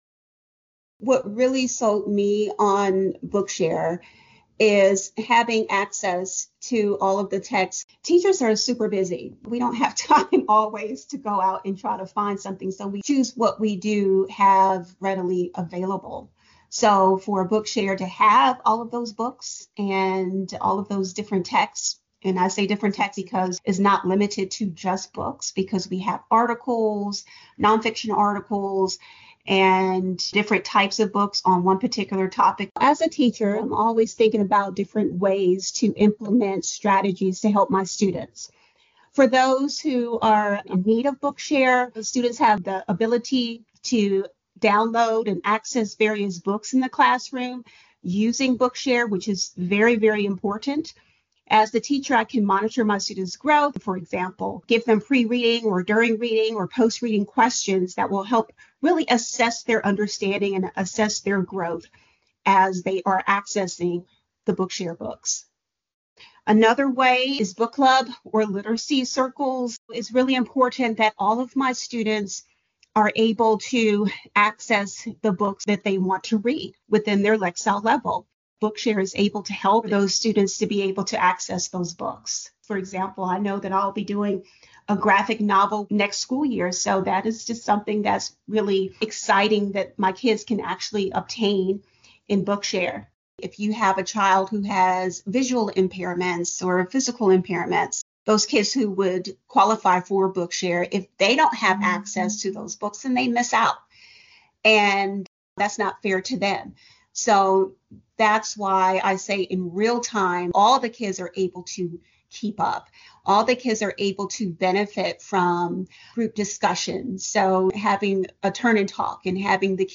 In these interviews
Teacher